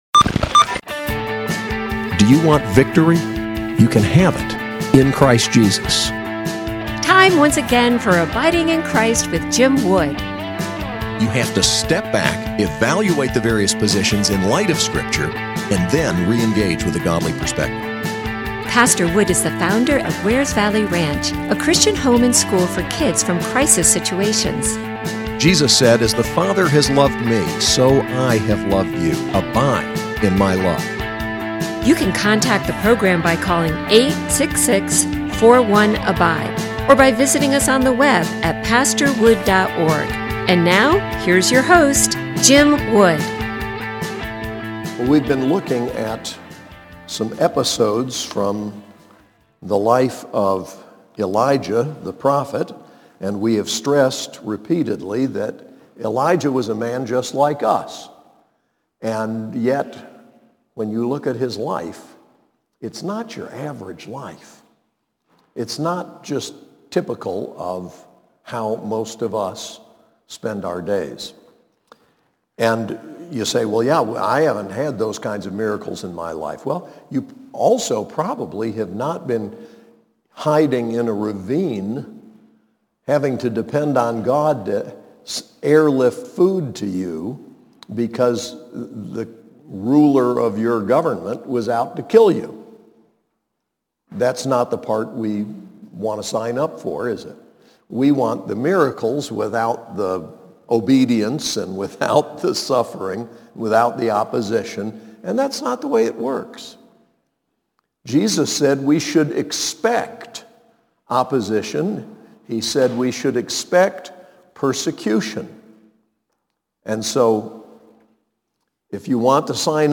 SAS Chapel: 1 Kings 21